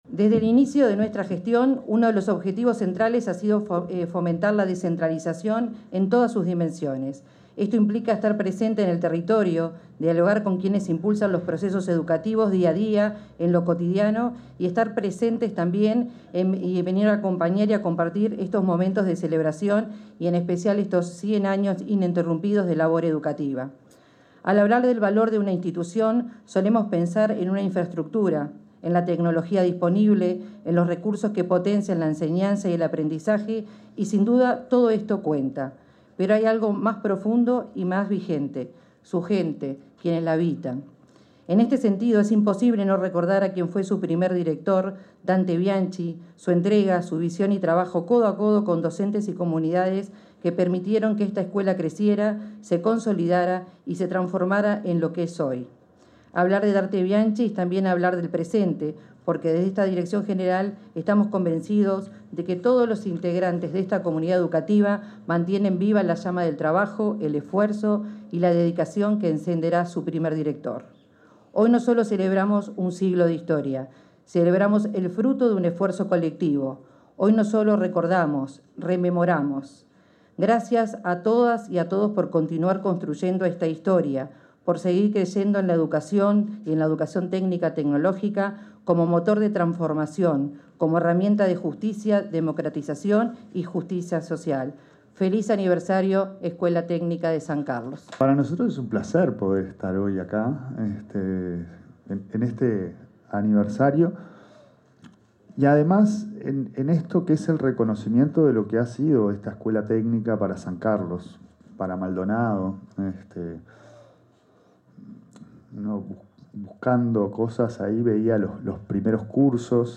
Palabras de autoridades de la educación en San Carlos 23/05/2025 Compartir Facebook X Copiar enlace WhatsApp LinkedIn El presidente de la Administración Nacional de Educación Pública, Pablo Caggiani, y la directora general de Educación Técnico-Profesional, Virginia Verderese, pronunciaron discursos en el acto por los 100 años de la creación de la Escuela Técnica de San Carlos.